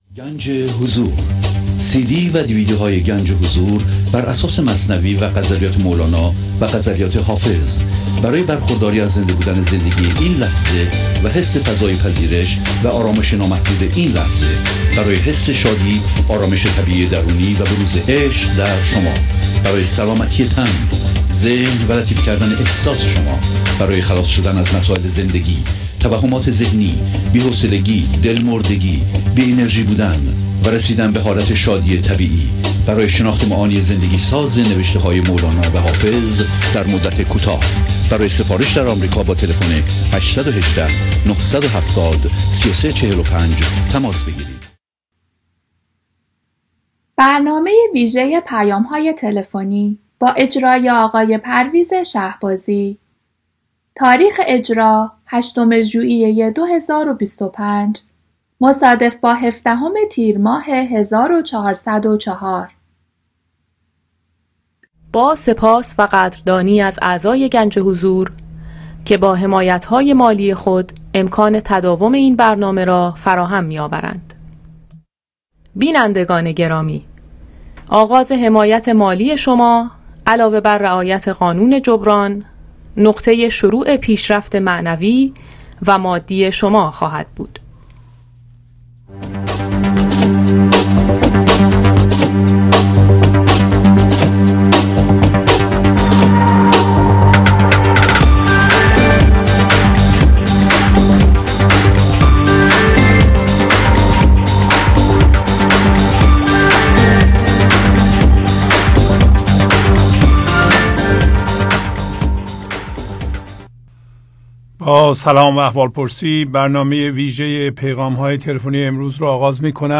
Ganj e Hozour Telephone messages